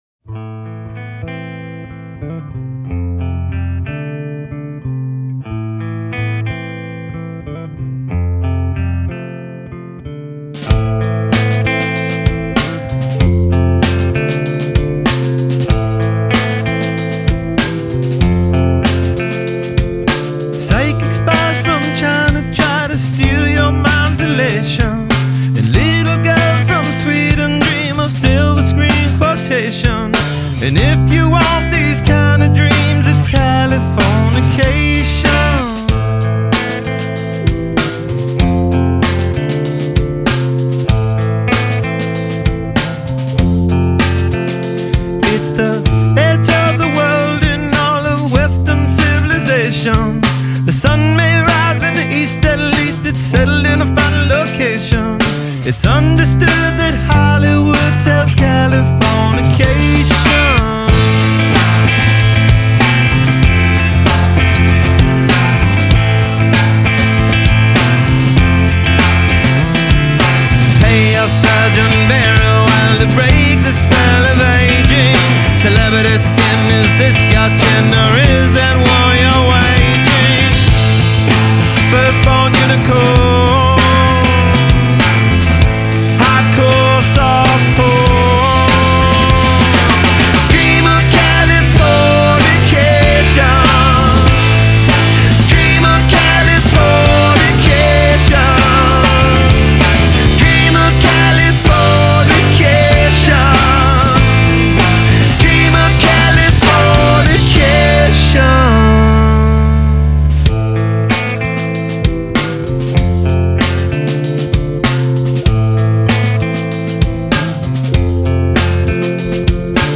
Rock 90er